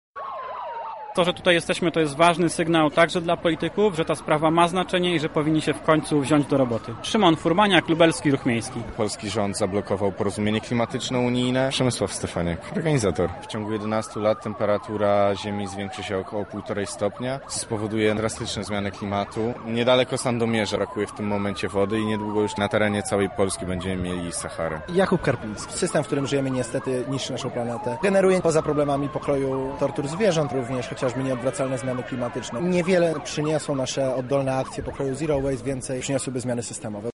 Na miejscu była nasza reporterka: